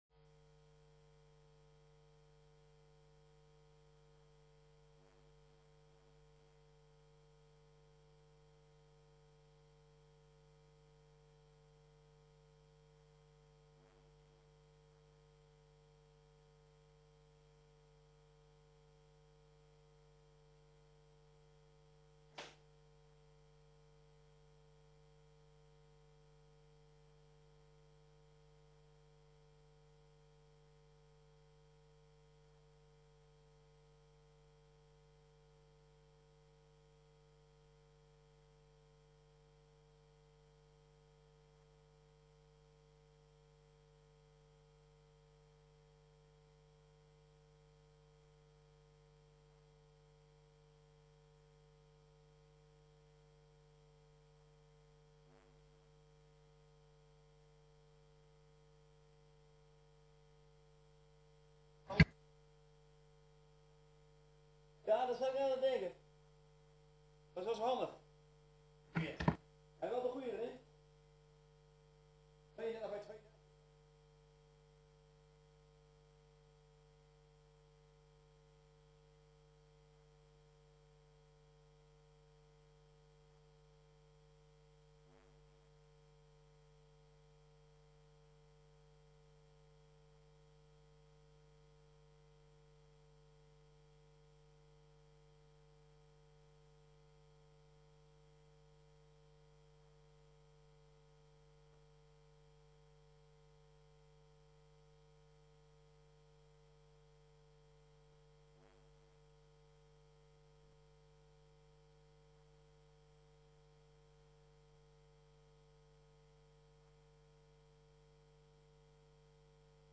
Raadsvergadering d.d. 25 maart 2014.
Datum: 25 mrt. 2014, 14:30 Locatie: Raadzaal Opties bij deze vergadering Print agenda Download documenten Legenda Opslaan in uw agenda Agenda Hoofdvergadering Raadzaal 1. 1.